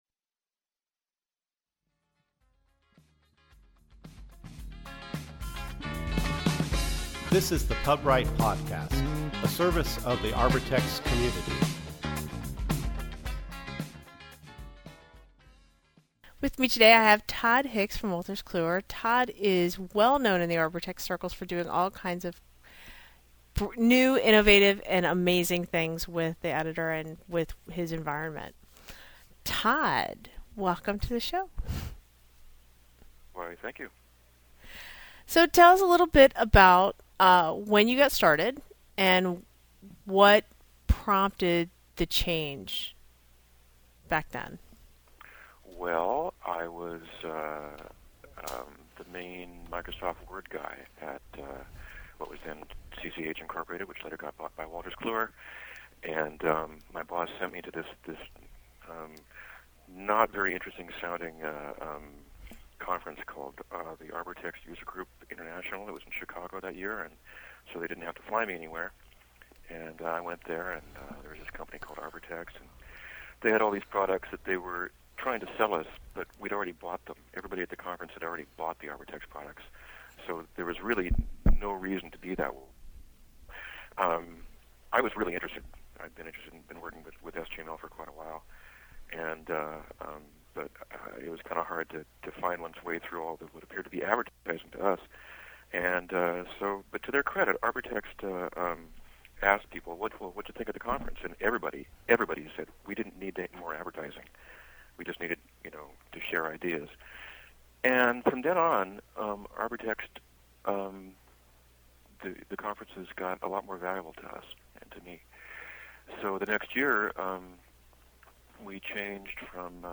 Podcast posted: Interview